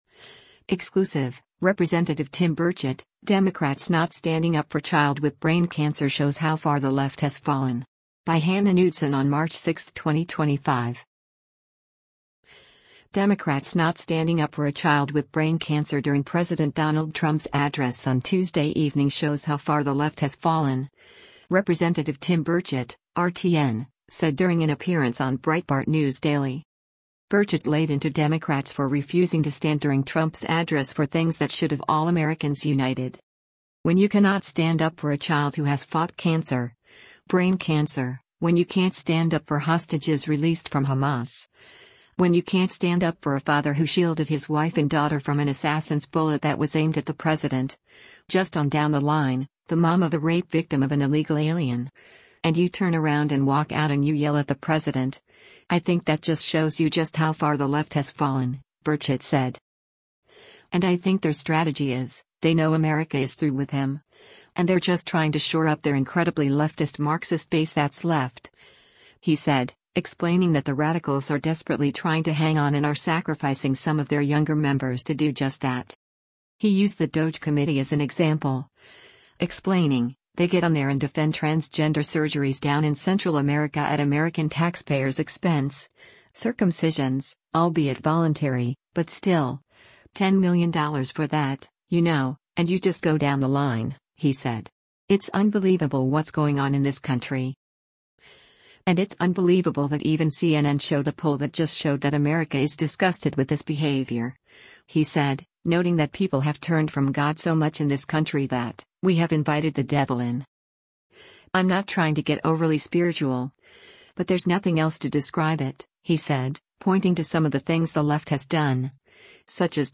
Democrats not standing up for a child with brain cancer during President Donald Trump’s address on Tuesday evening shows “how far the left has fallen,” Rep. Tim Burchett (R-TN) said during an appearance on Breitbart News Daily.